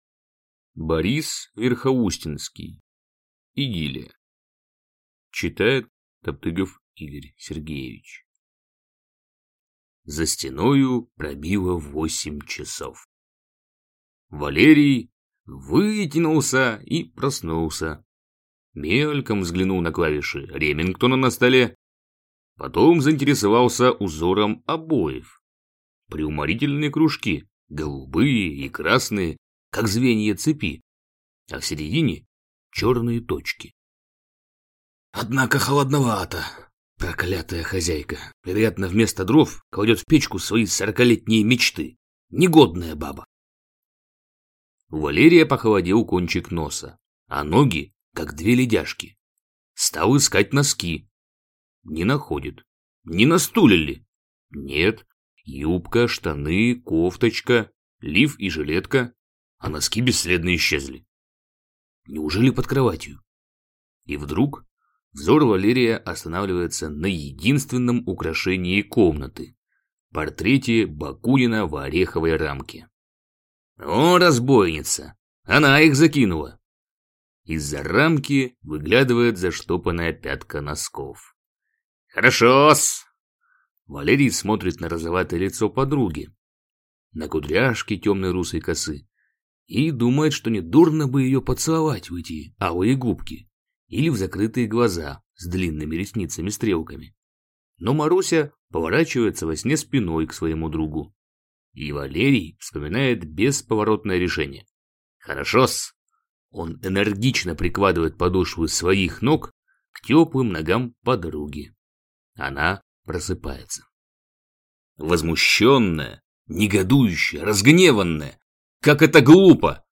Аудиокнига Идиллия | Библиотека аудиокниг